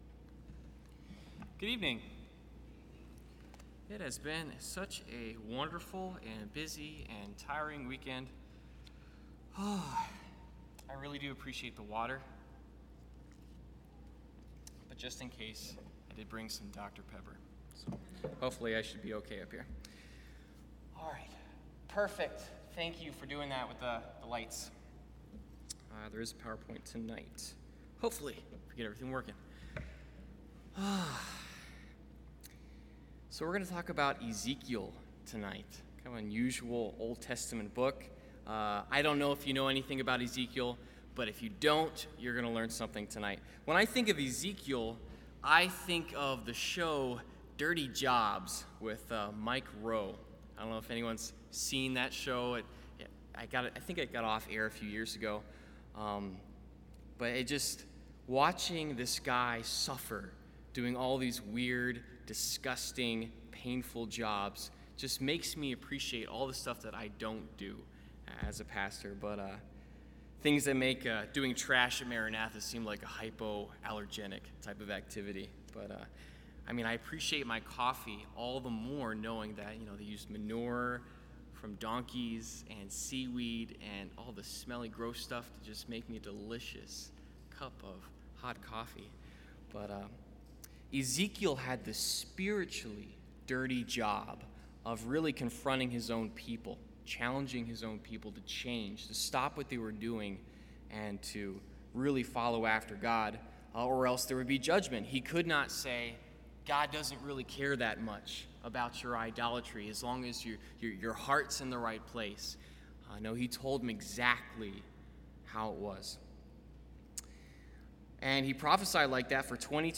Service Type: Sunday Evening Topics: Historicity of Scripture , Prophecy , Trustworthiness of God « Anger in the Christian Life